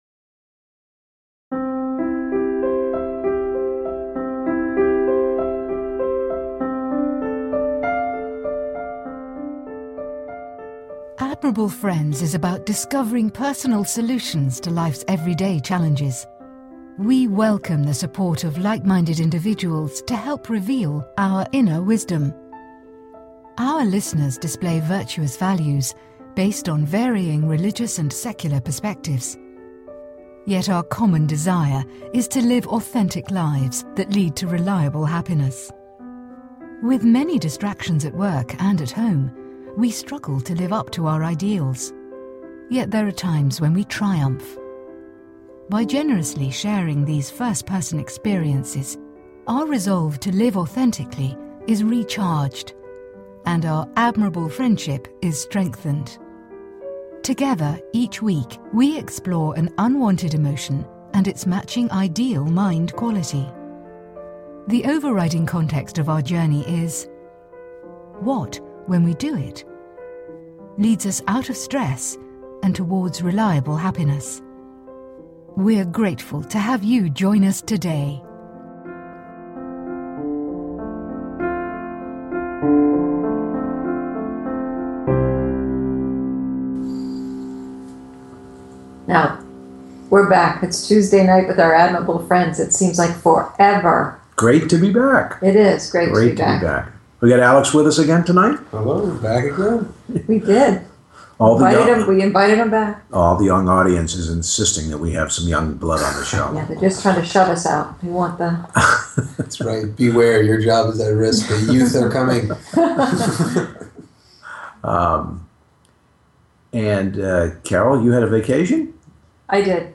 Talk Show Episode
This week: (2) Guests are Interviewed on Impatience and Endurance The Questions 1-When has patience served you well in your life? 2-When has impatience caused you stress in your life? 3-How do/would you apply the quality of Patience/Endurance to self-development?